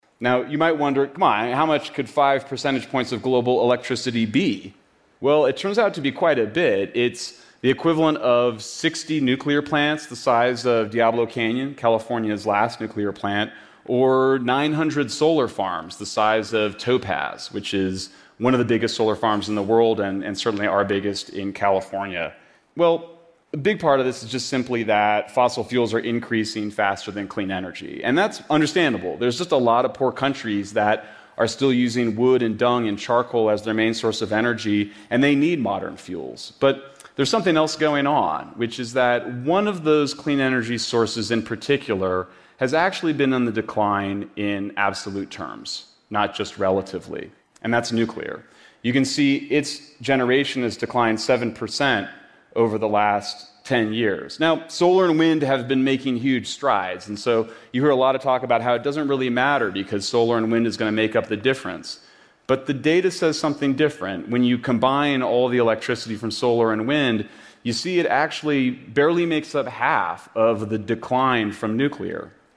TED演讲:恐惧核能是怎样伤害环境的(2) 听力文件下载—在线英语听力室